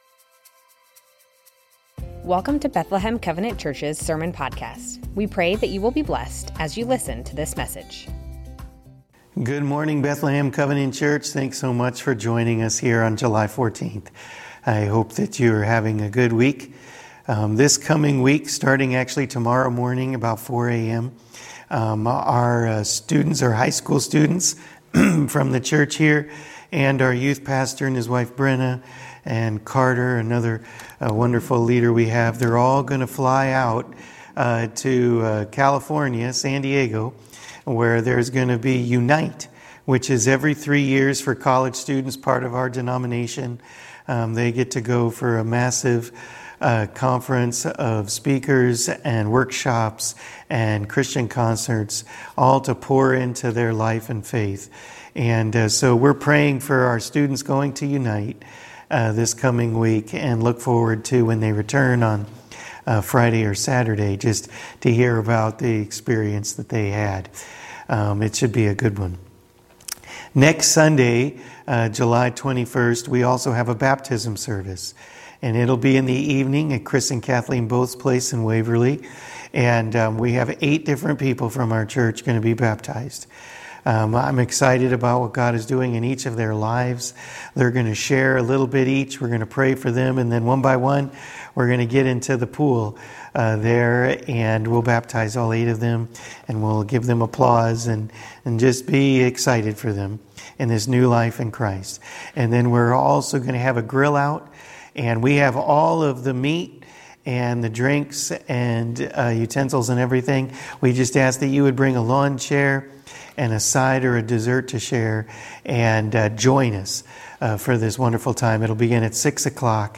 Bethlehem Covenant Church Sermons James: A People of Prayer Jul 14 2024 | 00:33:43 1x 00:00 / 00:33:43 Subscribe Share Spotify RSS Feed Share Link Embed